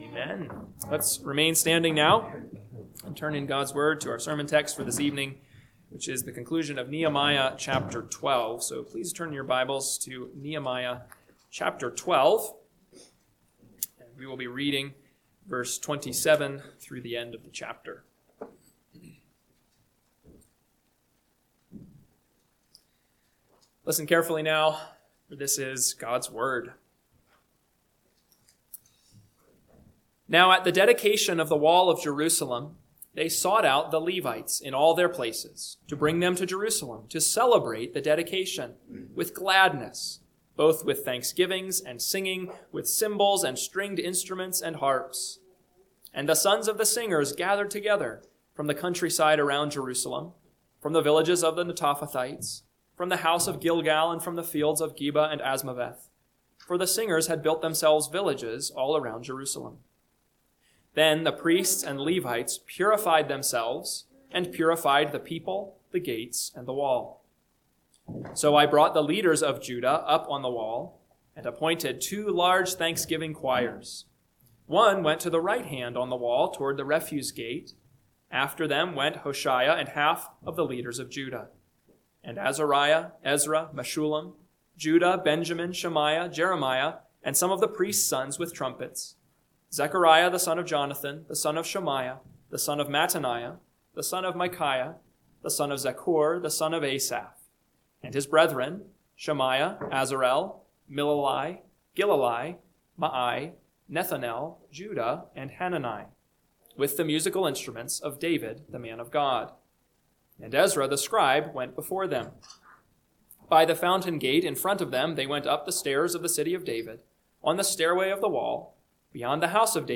PM Sermon – 11/9/2025 – Nehemiah 12:27-47 – Northwoods Sermons